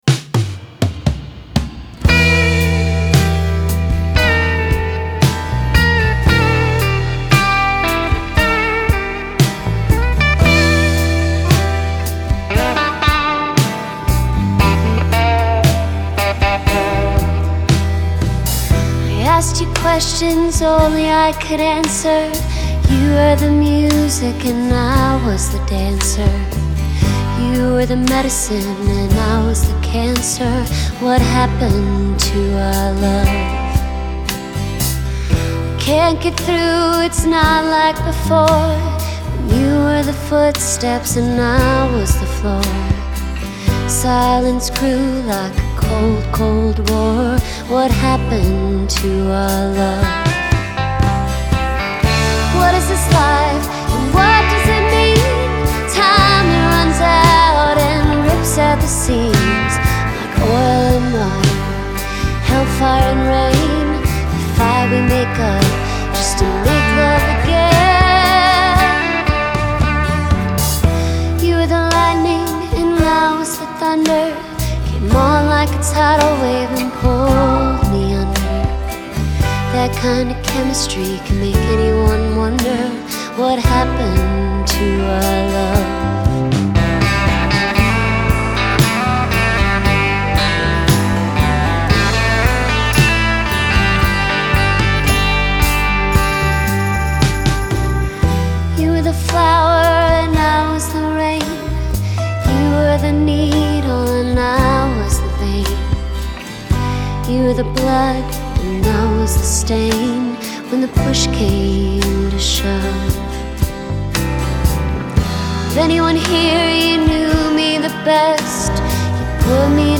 Genre : Country